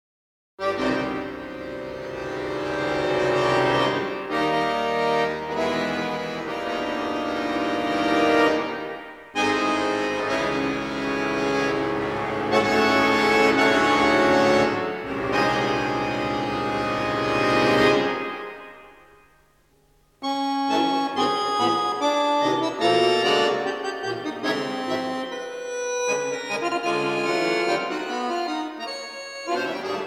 Maestoso - Allegro moderato -